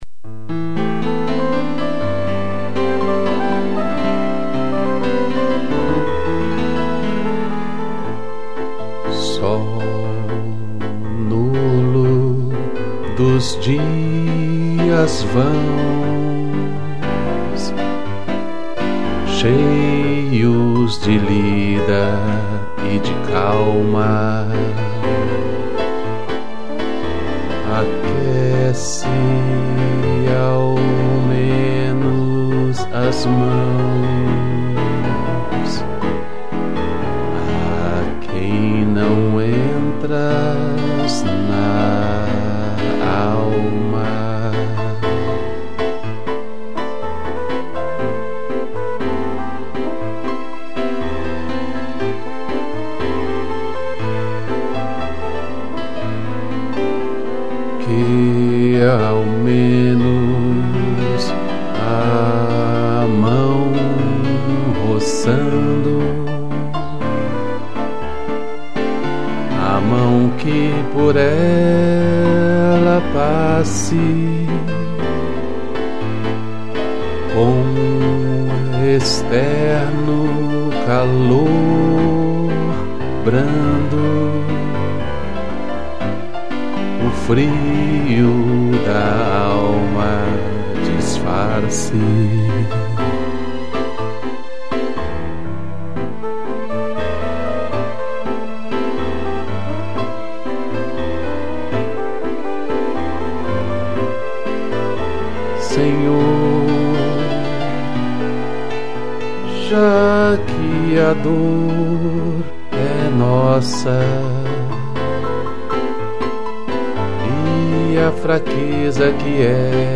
2 pianos, flugel_horn e strigns